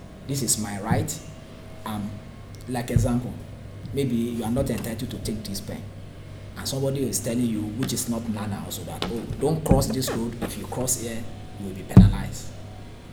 S1 = Bruneian female S3 = Nigerian male